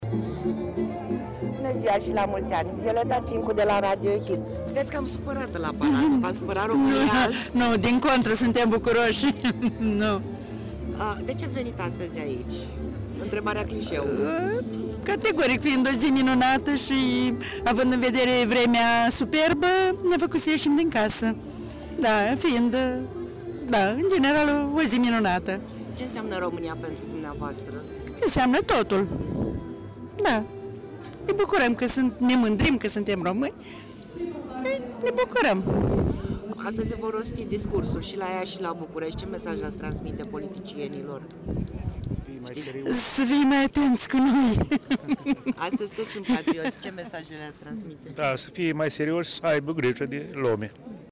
Oamenii au venit la parada și la ziua României.
O doamnă asista la paradă foarte supărată.